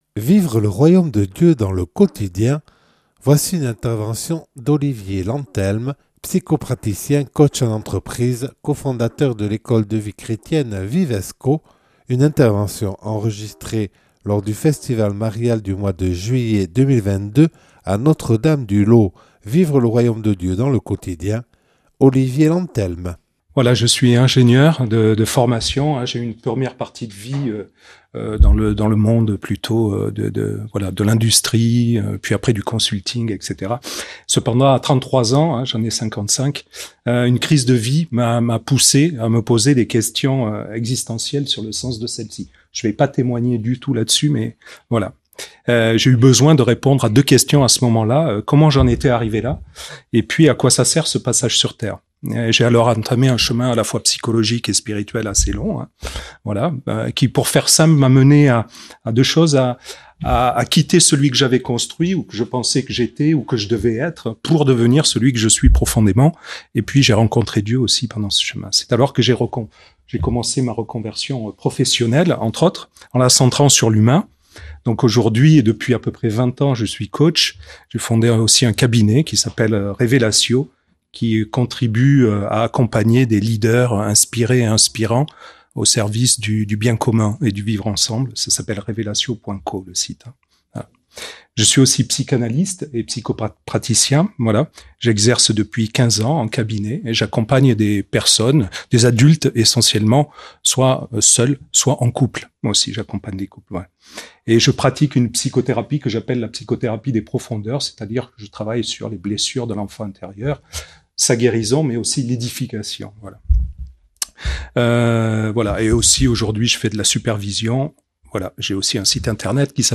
(Enregistré lors du Festival marial de juillet 2022 à Notre-Dame du Laus).